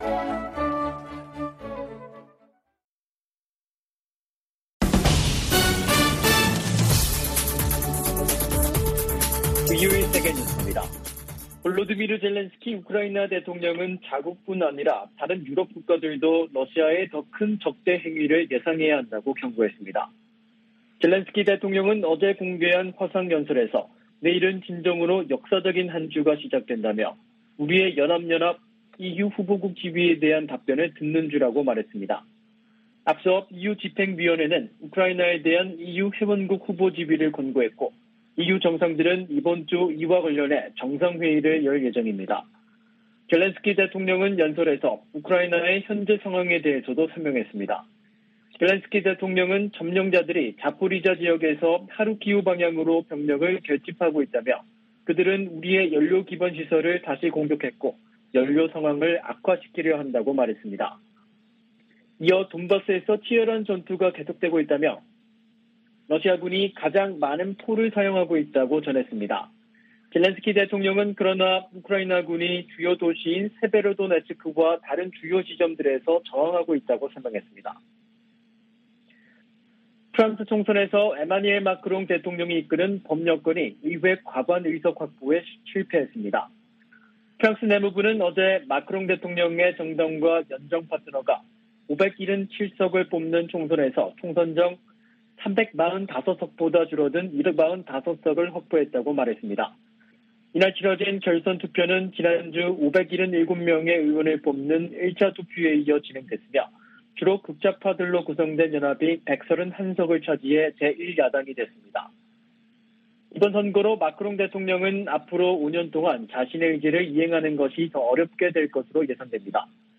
VOA 한국어 간판 뉴스 프로그램 '뉴스 투데이', 2022년 6월 20일 2부 방송입니다. 미국의 핵 비확산 담당 고위 관리가 오는 8월 NPT 재검토 회의에서 북한 문제를 다룰 것을 요구했습니다. 북한의 7차 핵실험에 관해, 정치적 효과를 극대화하는 데 시간이 걸릴 수 있다는 관측이 나오고 있습니다. 유엔의 의사결정 구조 한계 때문에 북한의 행동을 바꾸기 위한 국제적 단합에 제동이 걸릴 것으로 미 의회조사국이 진단했습니다.